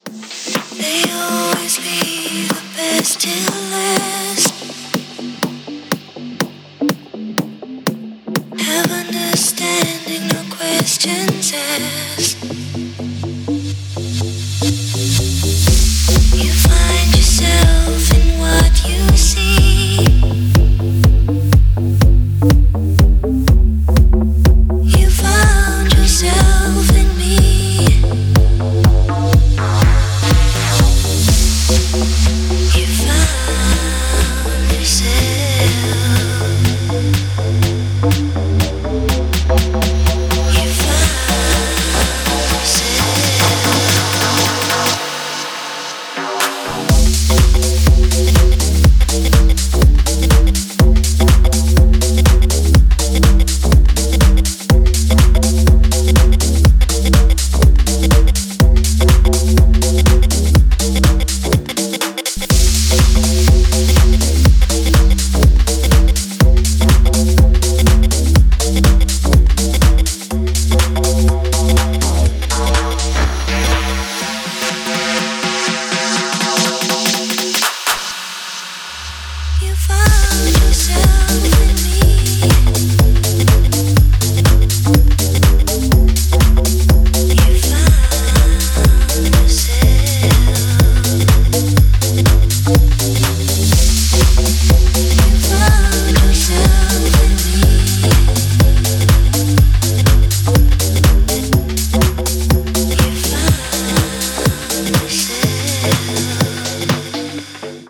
• Качество: 128, Stereo
remix
house
клубная музыка